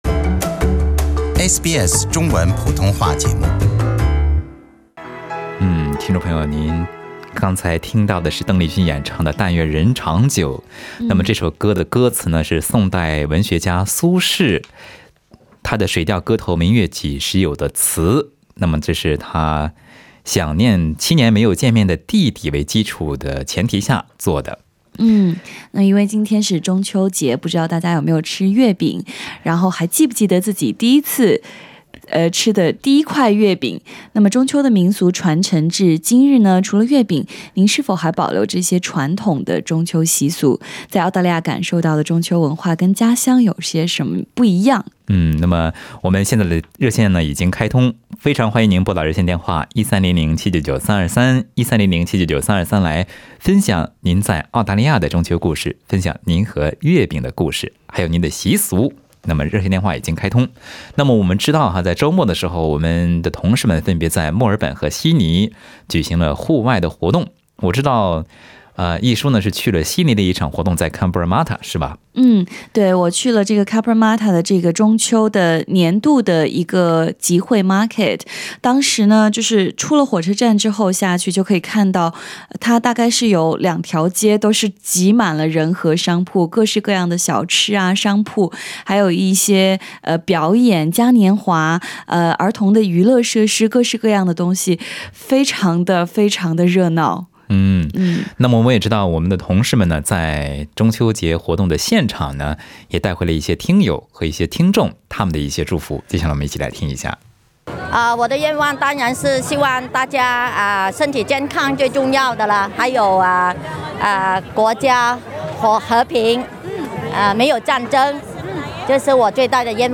本期中秋特别热线节目中，有听众朋友分享了他们体验过的中秋传统习俗，欢迎您点击收听。